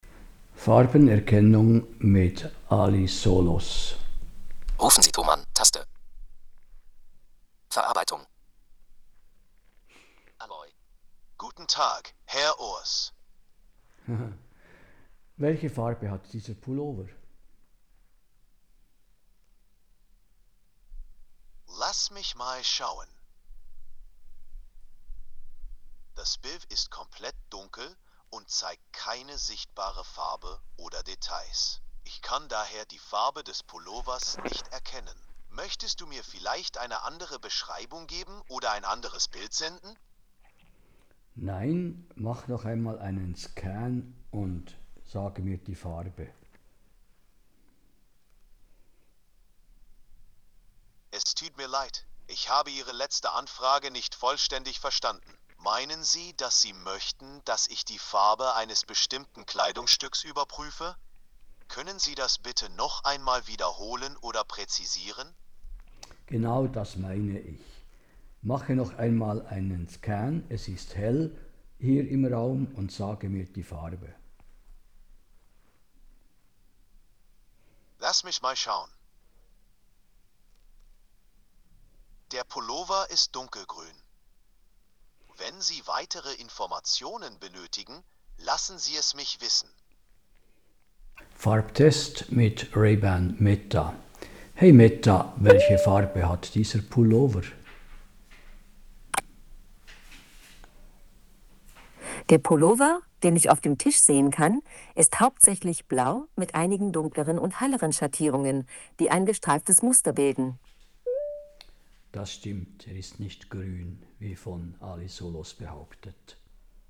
Die Audiobeispiele sind so aufgebaut, dass sie zuerst den Test mit der Ally Solos Brille wiedergeben und gleich anschliessend daran den Test mit der Ray-Ban Brille.